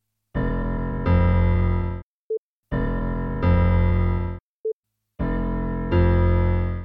The “Sliding Time Scale/Pitch Shift” is not quite right yet, but the sound quality is a lot better.
The attached file is a couple of low piano notes shifted down by 2 semi-tones.
The first part is using the standard Change Pitch effect at 44100 Hz
The second part is using the standard Change Pitch effect at 192000 Hz
The third part is using the “Sliding Time Scale/Pitch Shift” at 44100 Hz (Audacity ® 1.3.13-alpha-Jun 28 2010)